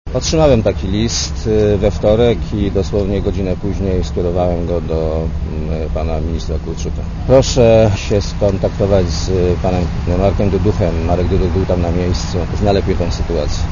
Mówi Leszek Miller(56Kb)